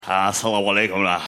Категория: Смешные реалтоны